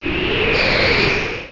strongWind.wav